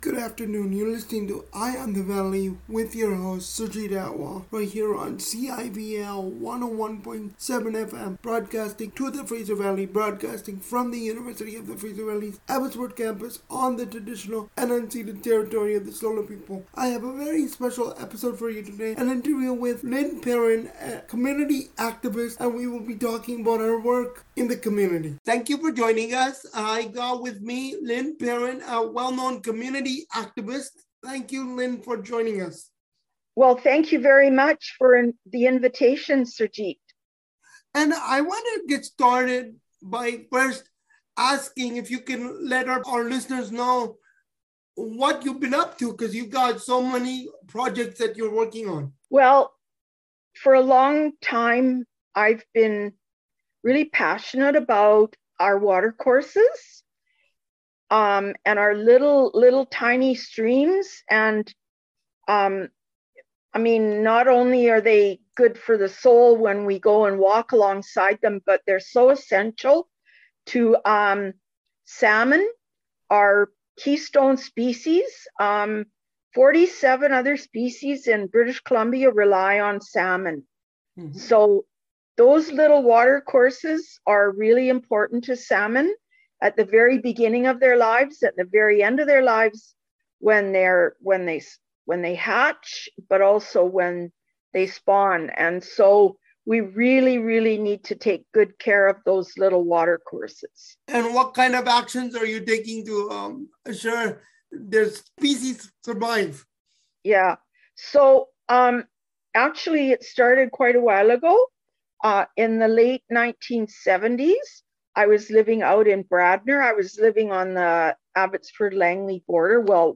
Community activist talks about importance of local watercourses